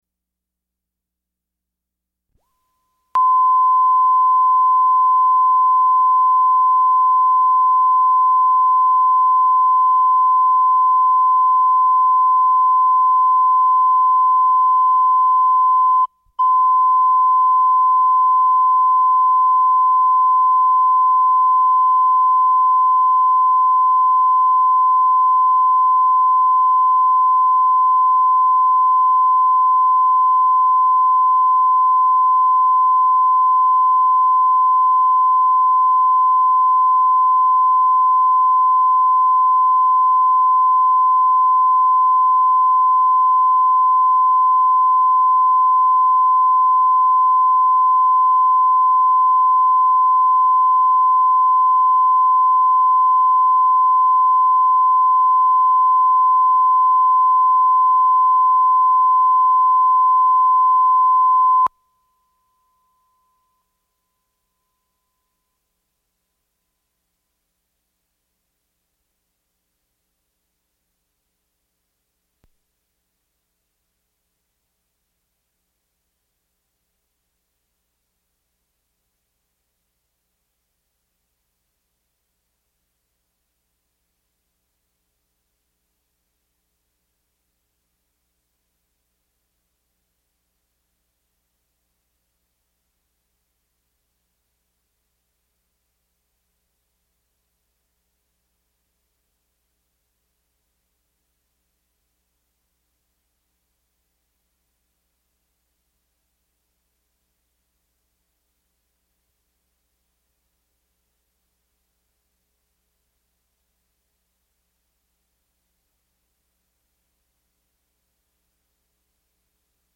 Impersonation
'SNL' cast member Will Ferrell